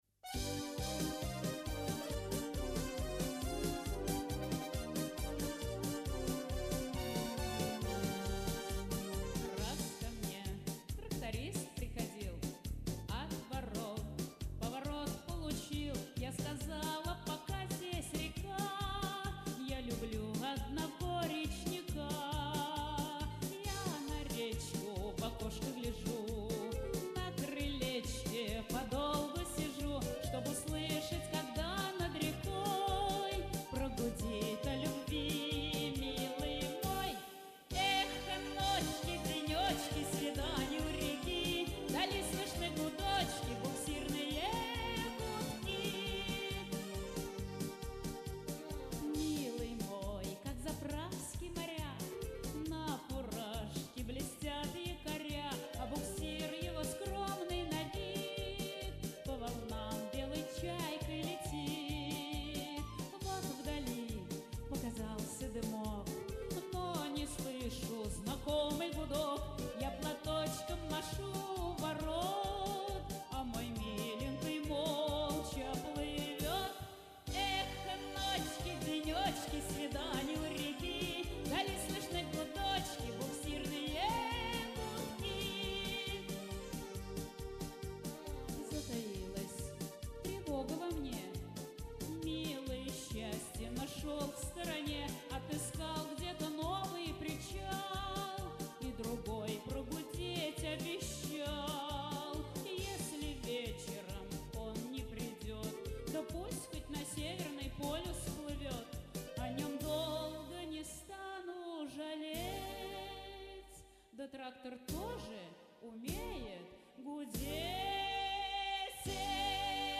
Шуточная песня.